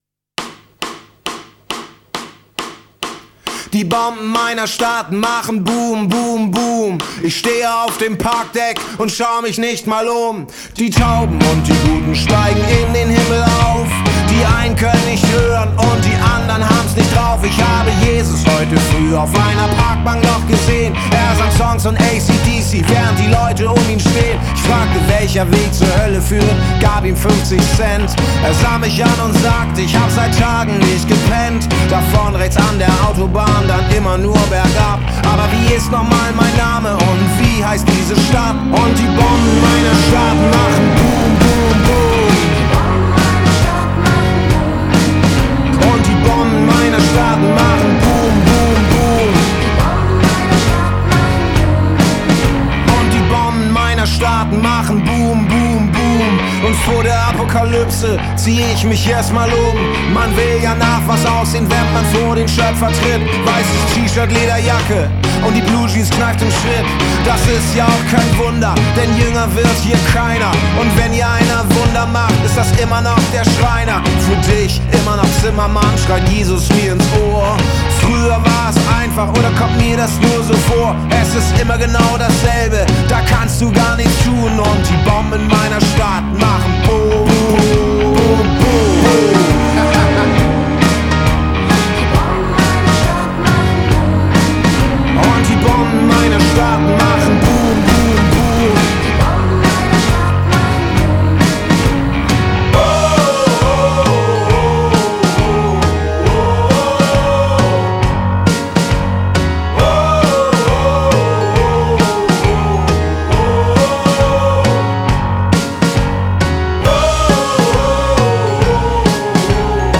has a totally different melodic vibe